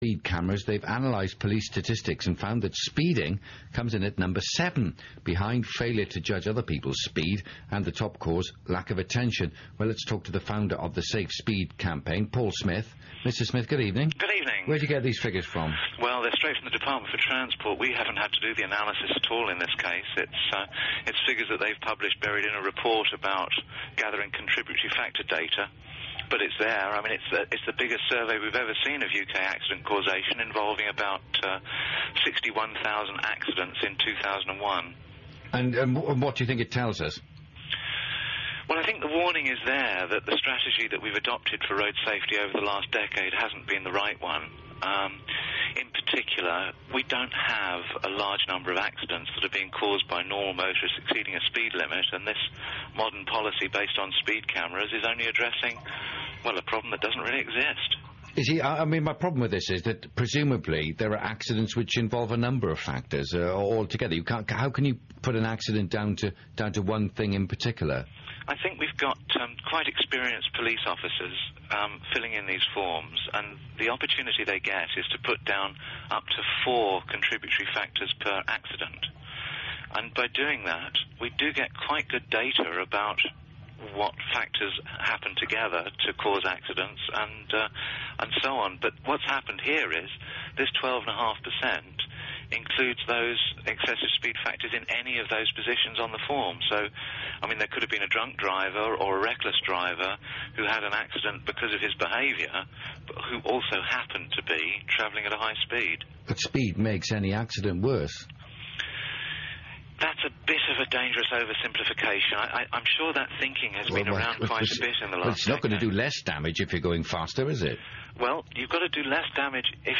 Here is a recording of the interview.
This was originally broadcast at about 9:15pm on 4th April 2004 on BBC Radio Five Live.